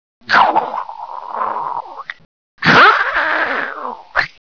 Wer nochmal mitraten möchte, kann sich ja zuerst die Tierstimmen anhören.